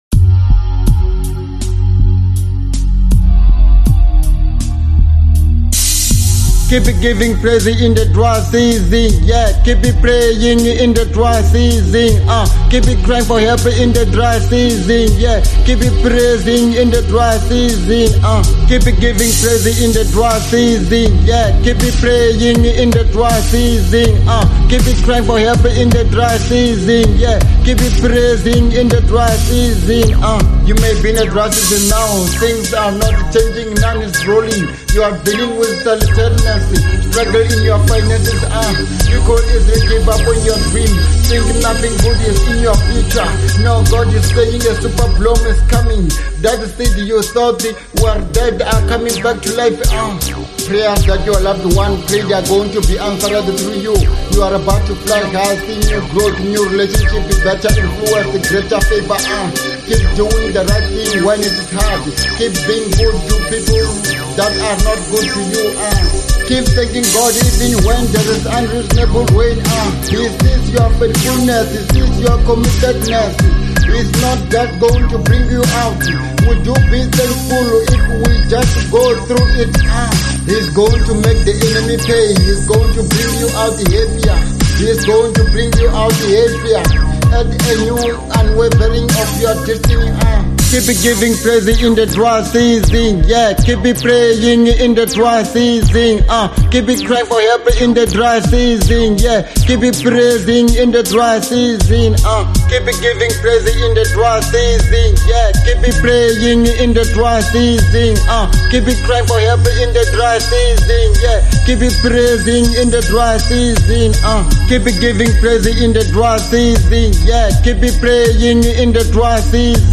02:13 Genre : Hip Hop Size